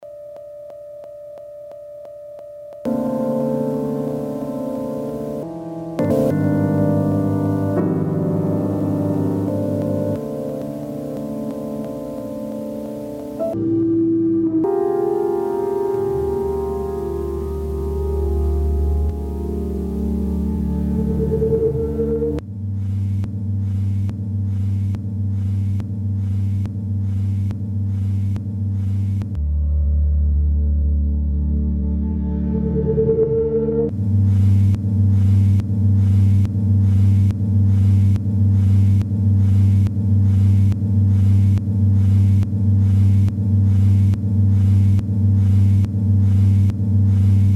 a boy / girl duet experimenting with samples and stuff